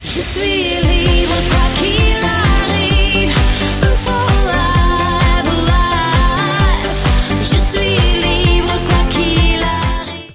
belgijska wokalistka